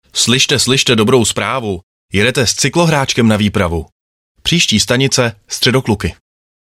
Na trase Beroun - Praha tak cestující nejen, že neměli problém dostat se do práce a z práce, ale ještě si mohli užít příjemného prostředí a za poslechu vtipných hlášení stanic jako například: